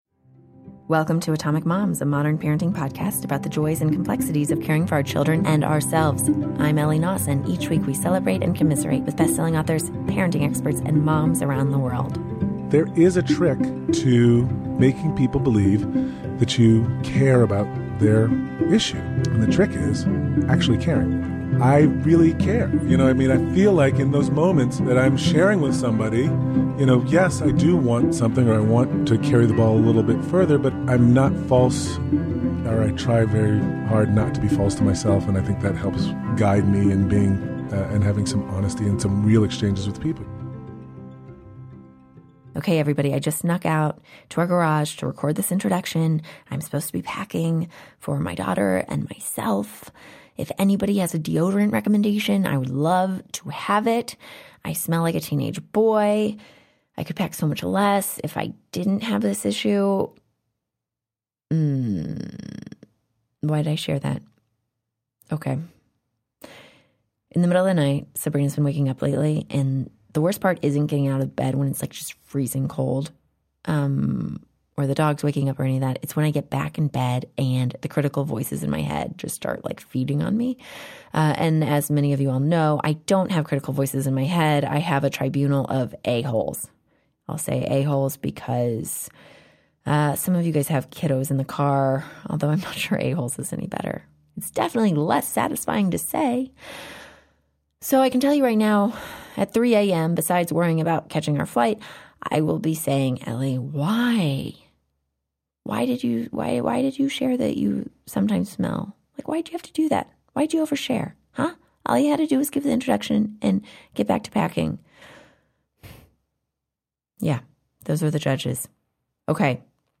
The interview portion of this episode originally released January 2016.